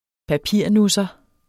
Udtale [ -nusʌ ]